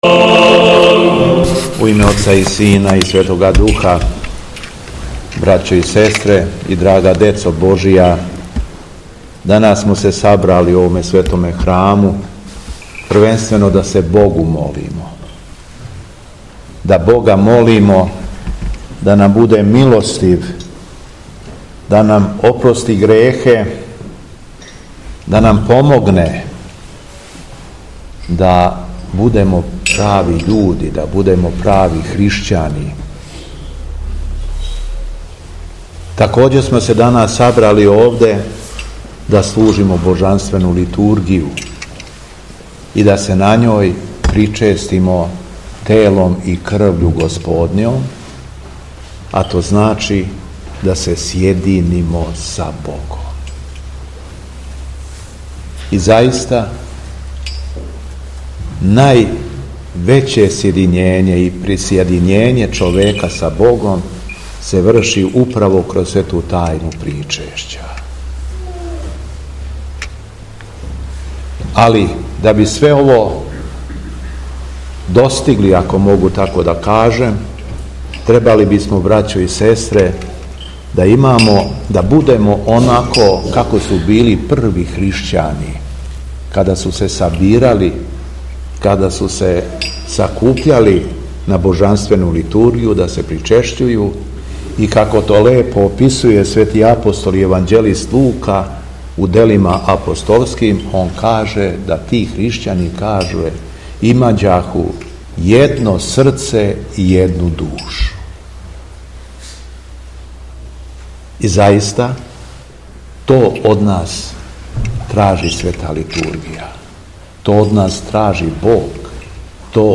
Беседа Његовог Преосвештенства Епископа шумадијског г. Јована
Након прочитаног зачала из Светог Јеванђеља Преосвећени Епископ се обратио верном народу беседом: